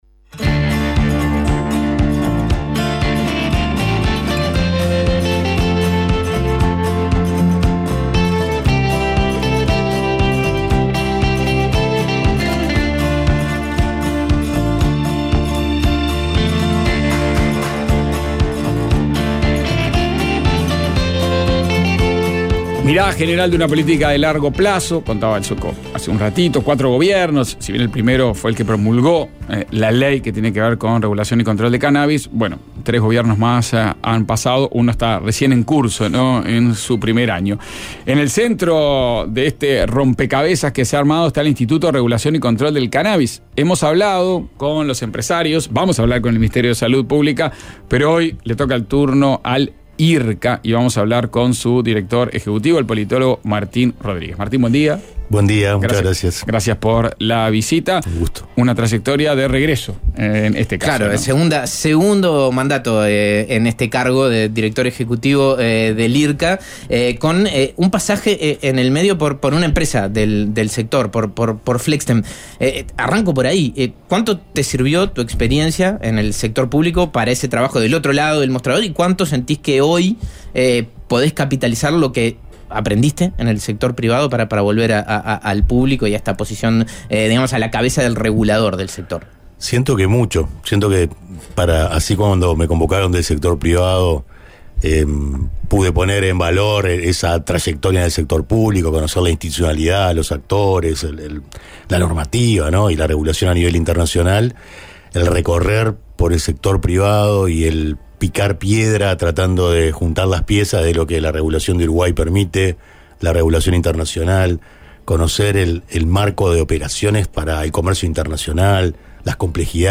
Ronda